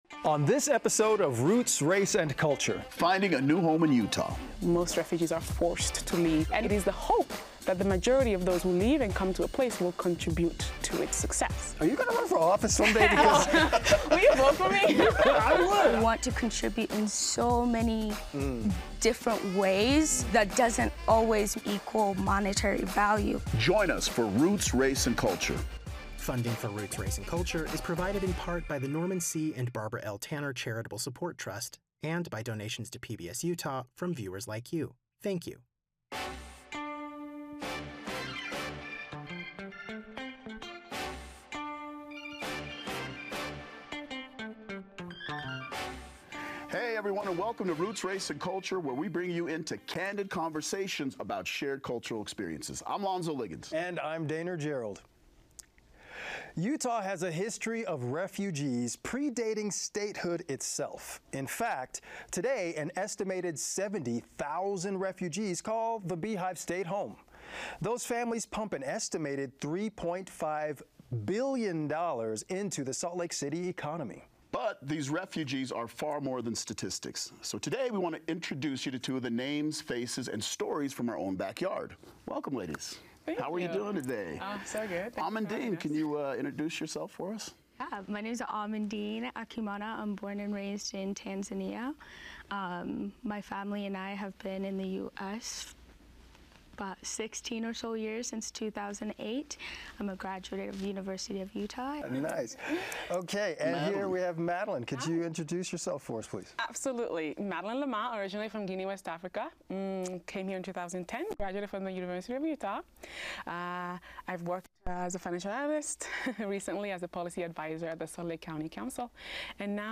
In this episode, our panel defines the terms immigrant, refugee, and migrant, and discusses the founding of America by refugees.